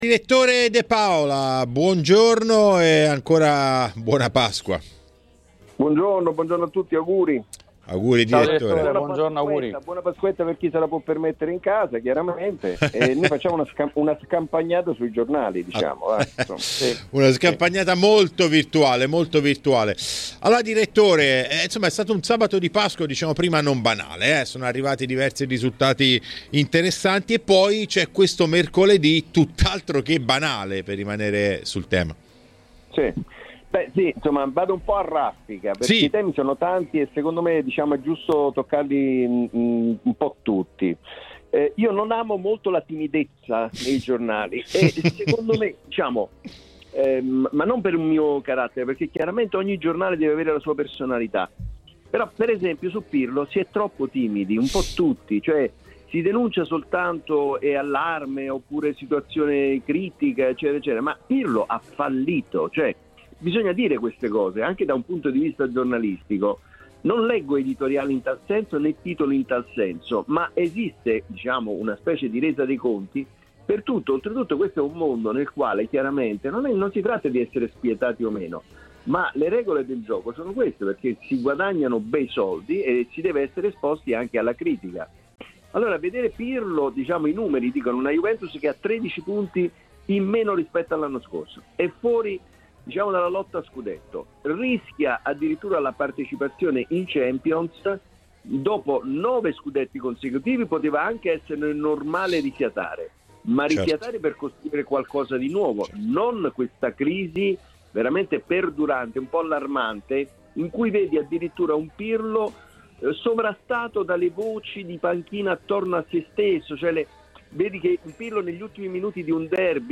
è intervenuto a TMW Radio nel suo consueto appuntamento dell'editoriale del lunedì.